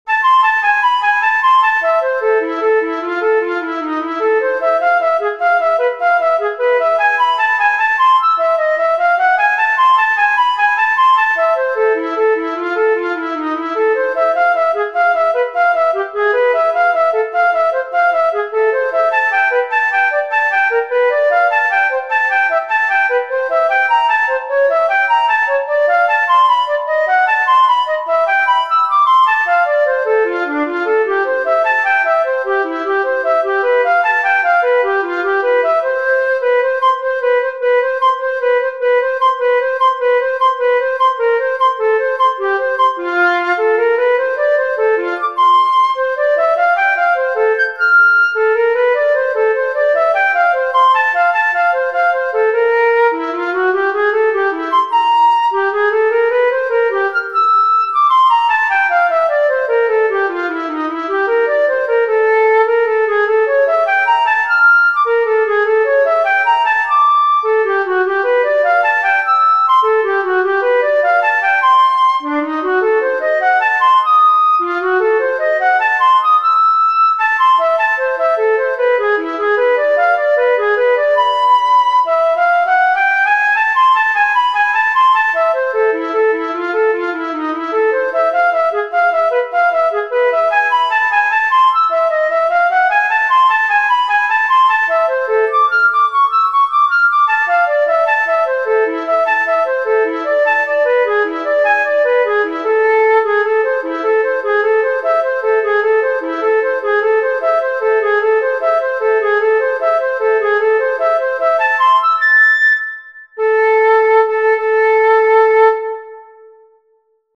Allegro
Ütemmutató: 4/4 Tempo: 120 bpm
Előadói apparátus: szóló fuvola